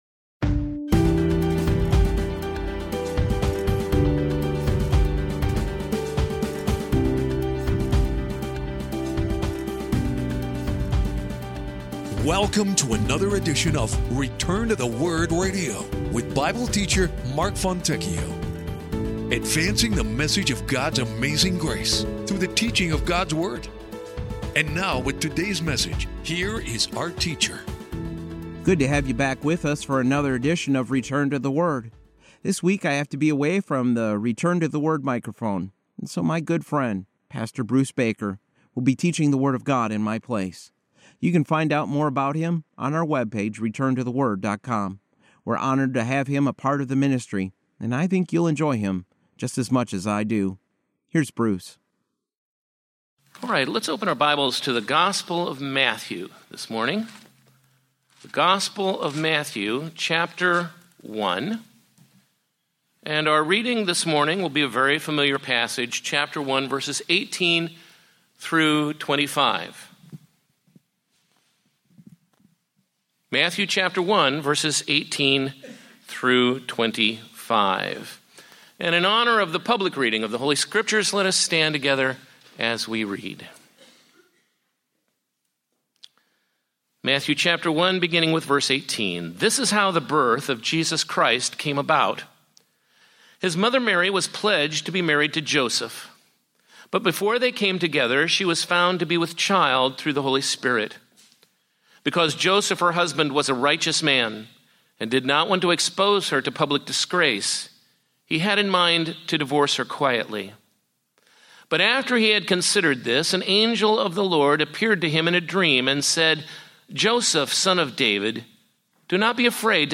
Teaching God's Word and advancing the message of His amazing grace one verse at a time.